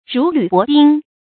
rú lǚ bó bīng
如履薄冰发音
成语正音履，不能读作“fù”；薄，不能读作“báo”。